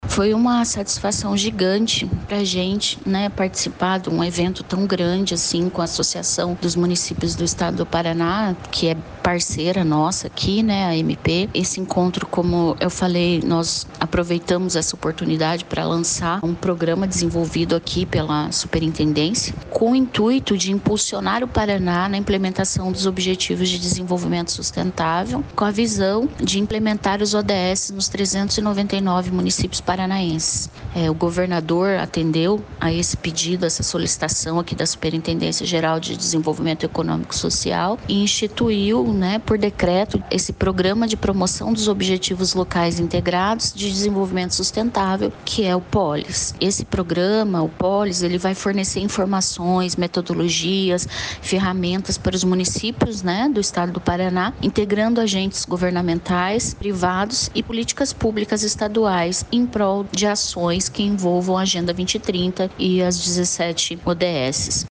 Sonora da superintendente-geral da SGDES, Keli Guimarães, sobre o programa que visa acelerar a adesão de municípios aos objetivos da ONU